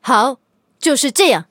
LT-35强化语音.OGG